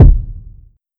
Kick (Miss Me).wav